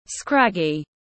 Gầy thiếu sức sống tiếng anh gọi là scraggy, phiên âm tiếng anh đọc là /ˈskræɡ.i/ .
Scraggy /ˈskræɡ.i/